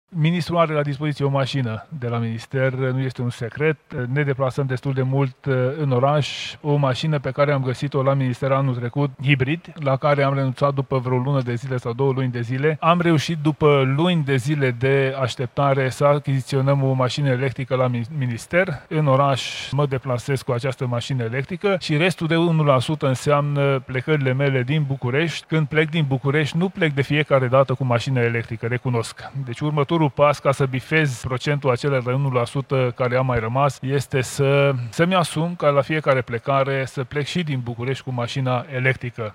La Salonul Auto, însă, ținut la ROMEXPO, oficialii au venit cu mașinile. Ministrul Mediului, Tanczos Barna: